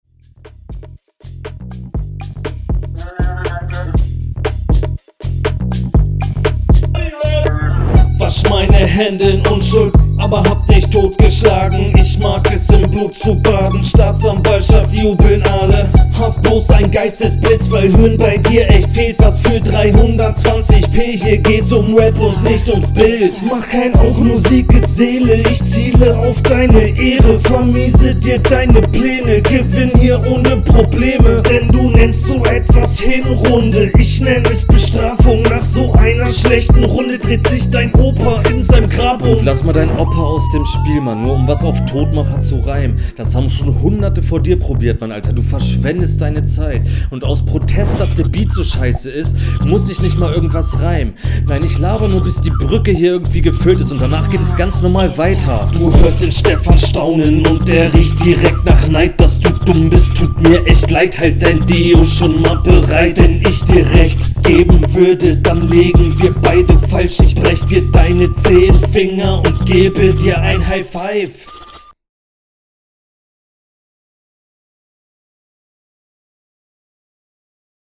Erstmal, der Beat ist mieees geil, feier ich komplett.